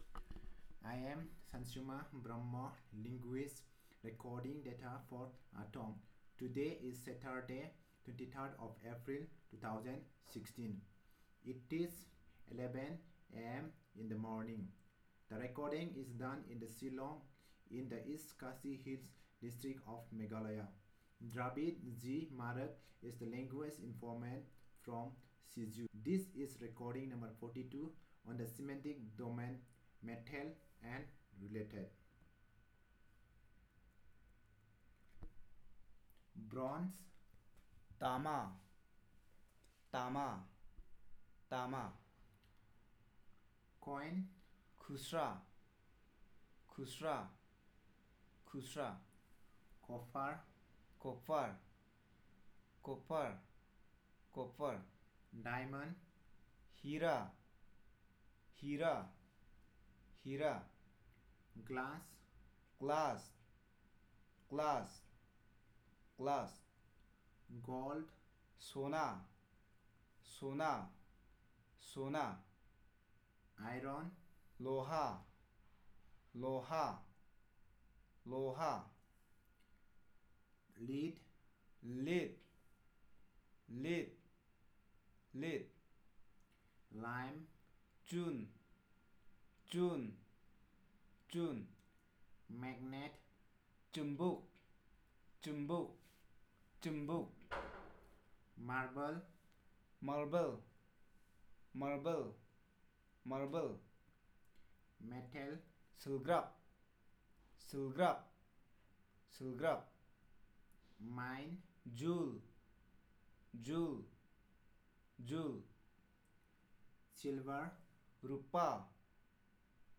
Elicitation of words about metal and related